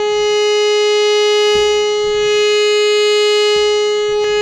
52-key16-harm-g#4.wav